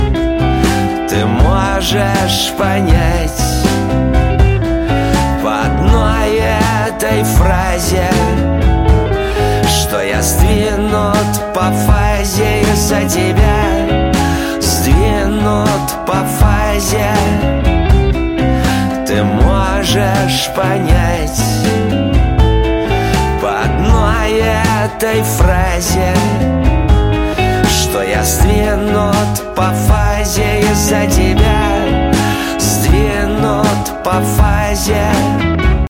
• Качество: 128, Stereo
рок